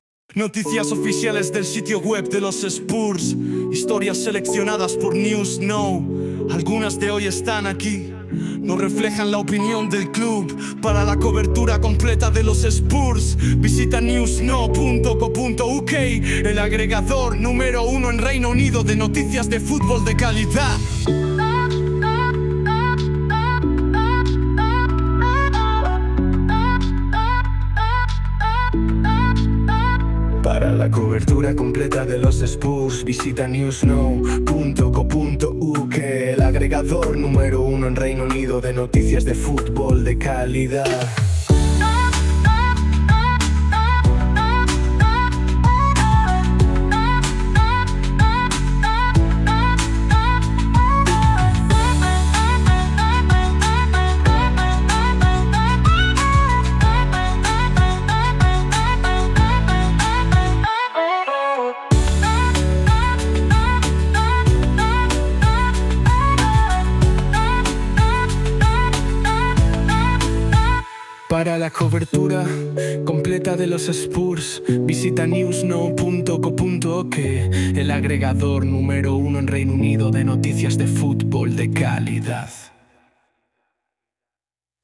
Hip-hop anthem in Spanish about Tottenham Hotspur website